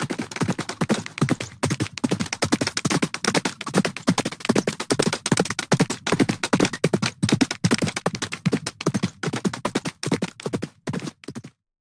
horse-galop-1.ogg